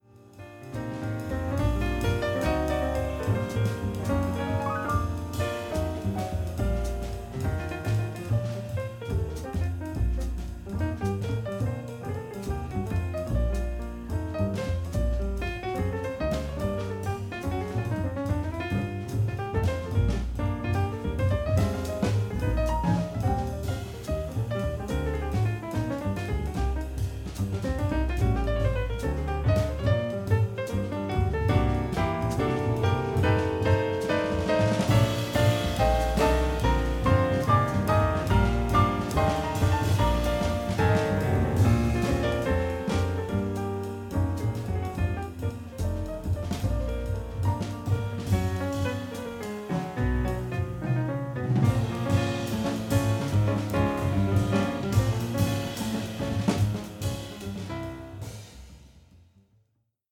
piano
contrebasse
batterie